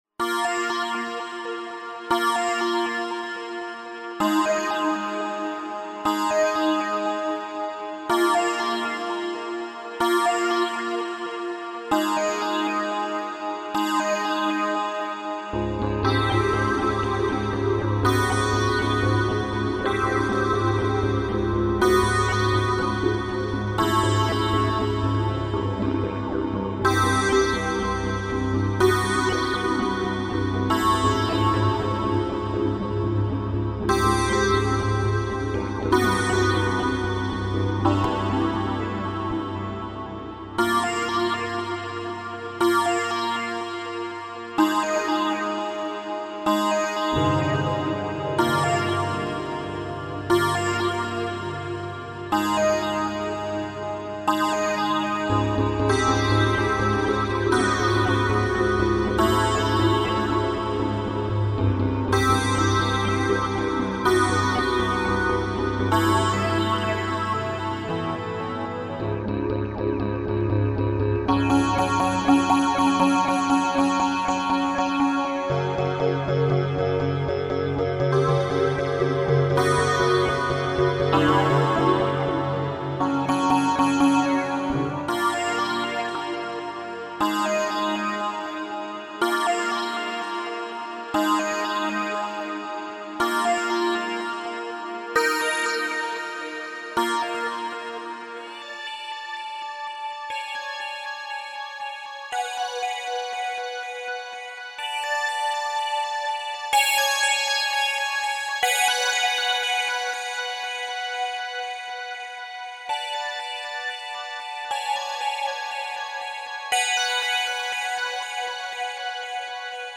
DARK AMBIENT SPACE